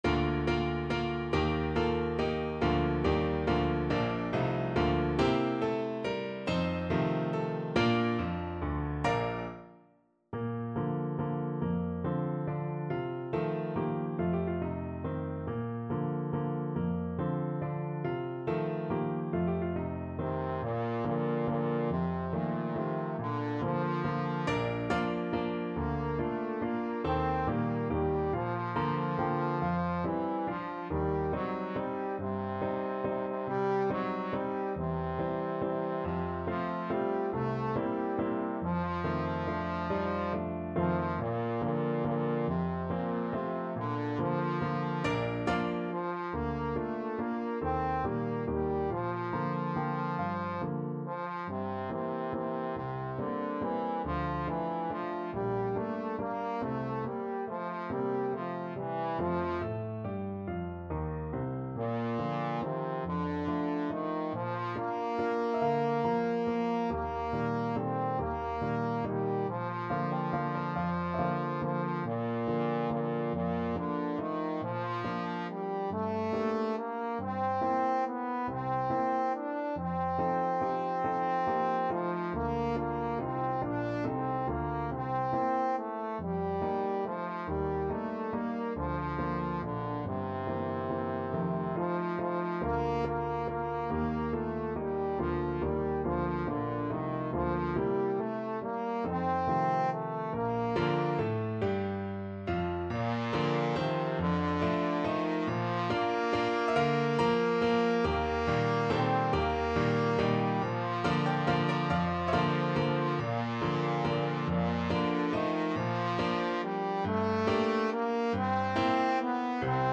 3/4 (View more 3/4 Music)
~ = 140 Tempo di Valse
Traditional (View more Traditional Trombone Music)